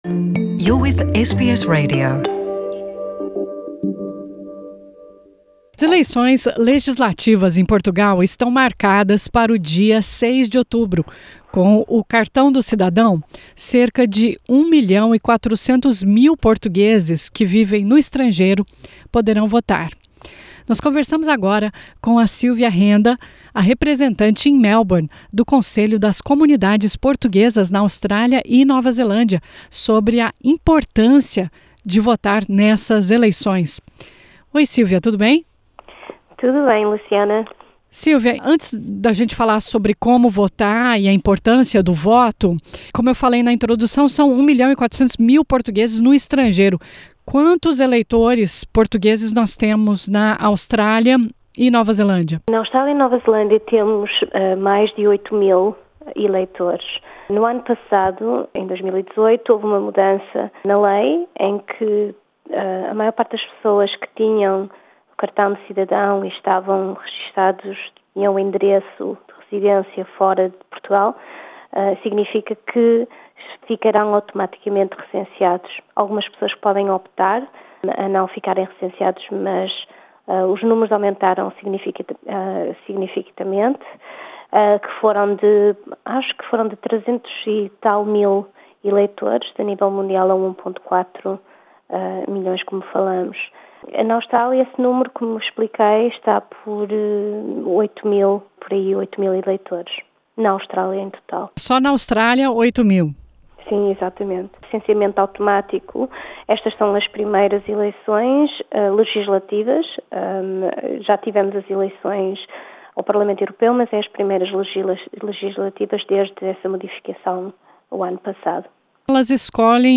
Ouça a entrevista clicando no botão play da foto que abre essa reportage ou leia abaixo tudo o que precisa saber para votar.